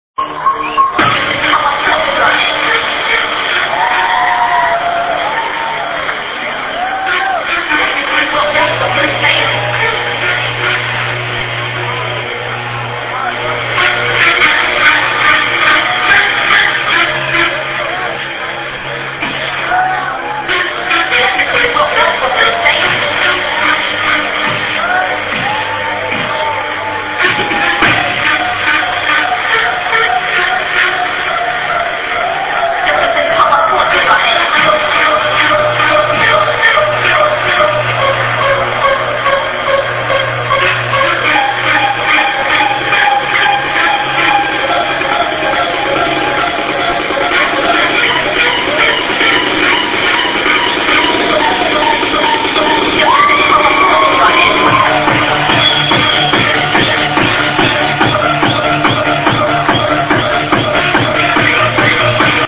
Can`t really hear what the "text" is..
this track was recorded in a Club in Berlin last year ... thats all i know